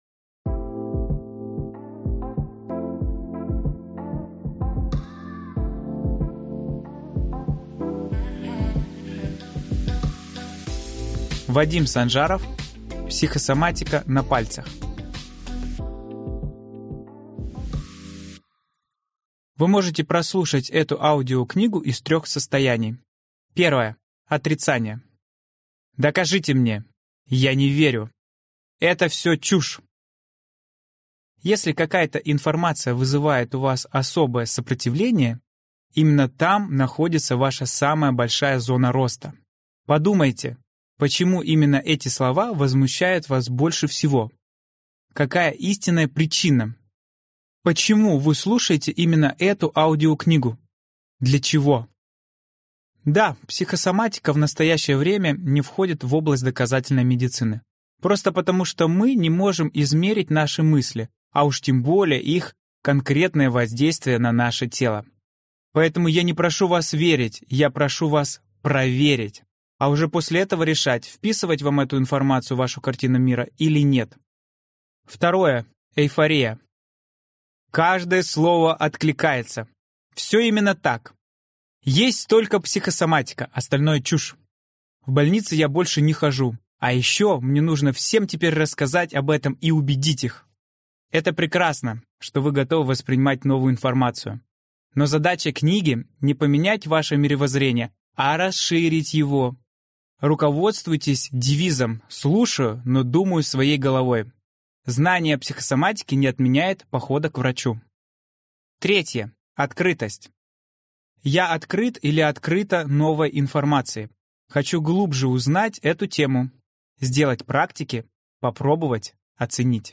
Аудиокнига Психосоматика на пальцах. Не верить, а проверить!